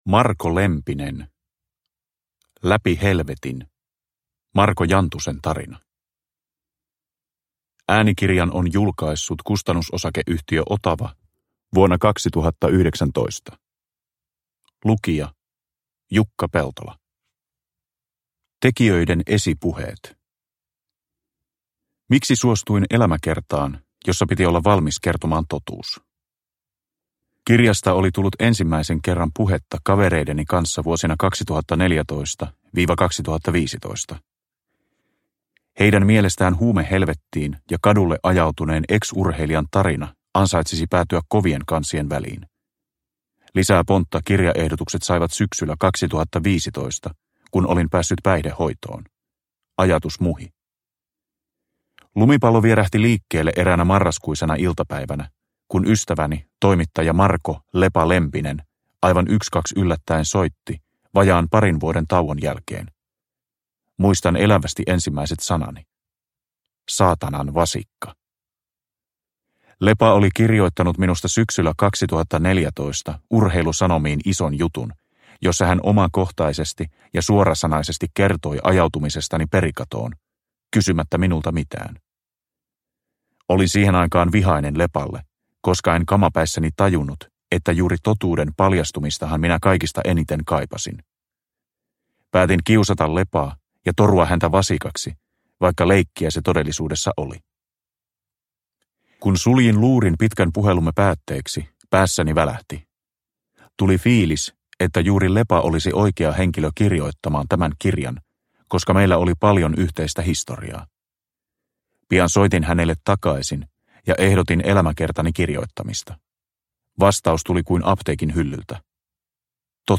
Läpi helvetin – Ljudbok – Laddas ner
Uppläsare: Jukka Peltola